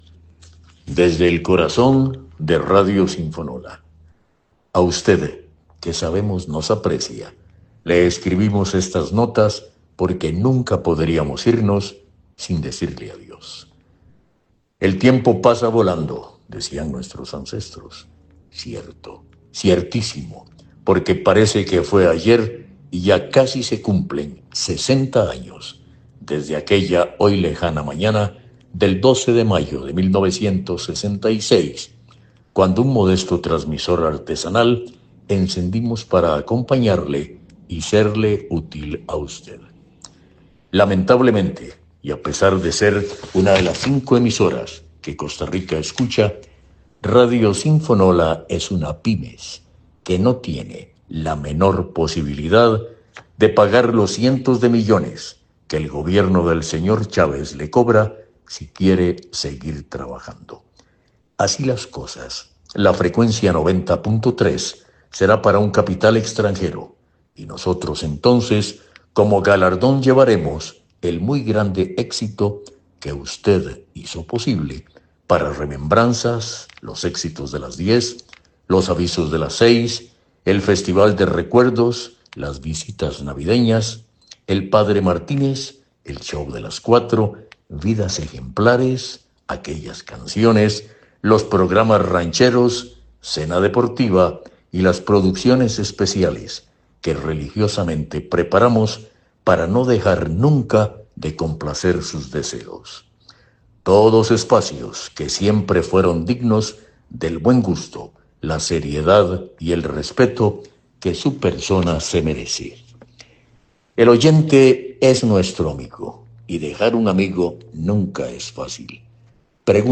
Audio-de-despedida-Sinfonola.mp3